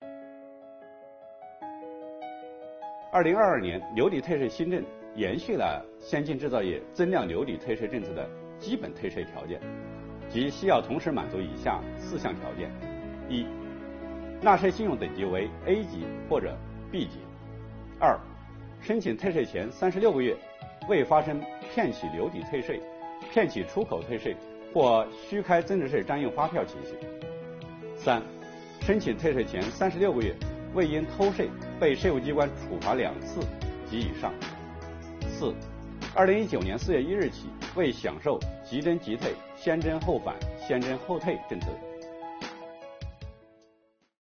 日前，新一期“税务讲堂”第五讲开讲。国家税务总局货物和劳务税司副司长刘运毛担任主讲人，详细解读了有关2022年大规模留抵退税政策的重点内容以及纳税人关心的热点问题。